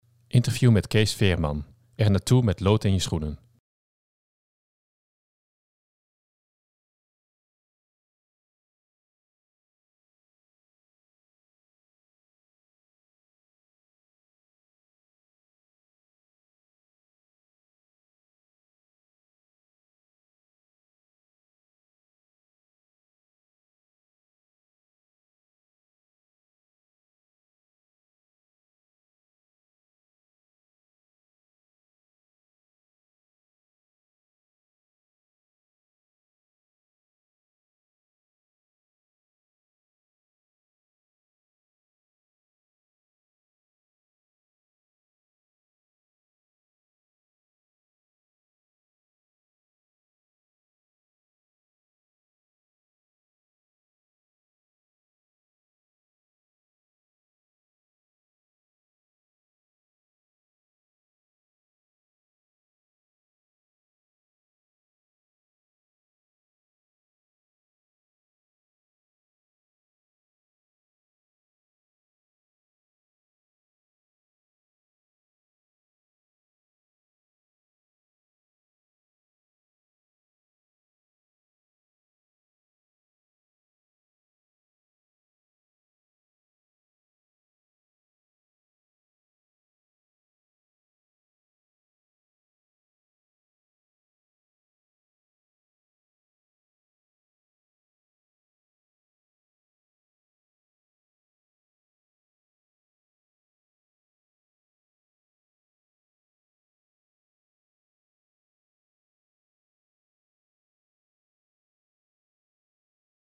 Interview met Cees Veerman